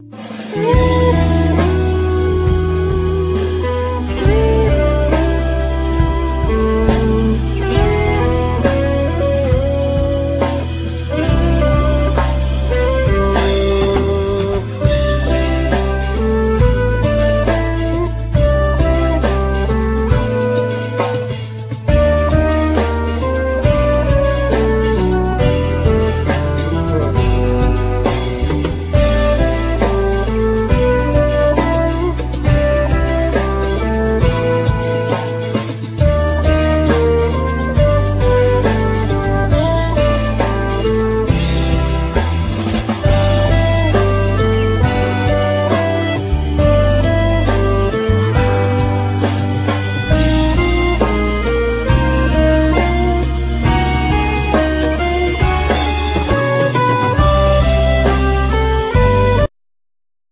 Flute,Tin & Low whistles,Bodhram
Guitar,Bocoder voice
Mandlin,Bouzoki,Banjo,Sitar,Percussions,Programming
El.bass
Drums
Accordion
Keyboards
Violin
Vocal,African percussions